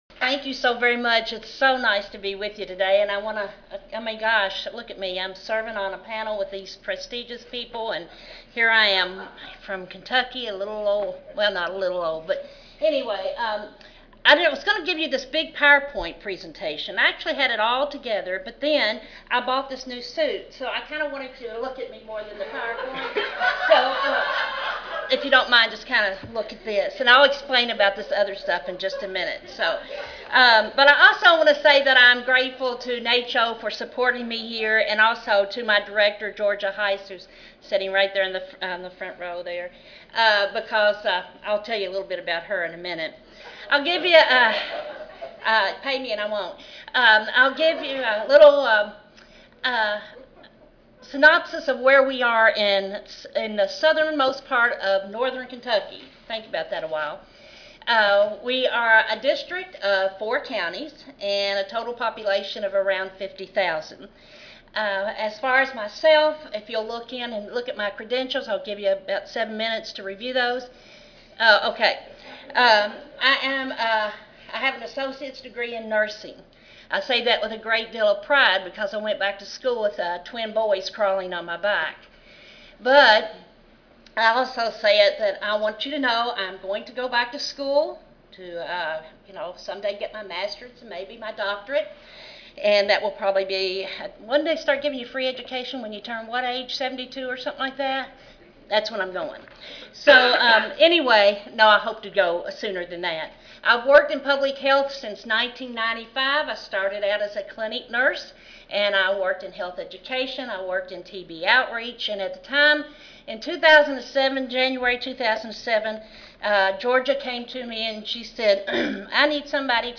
Session Objectives: By the end of this panel session, participants will be able to: 1) Describe the key changes made in updating the NPHPSP assessments; 2) Describe how the instruments have been used in practice; and 3) Describe how knowledge gained from the NPHPSP can be translated into opportunities for improved public health practice or help to shape public health policy.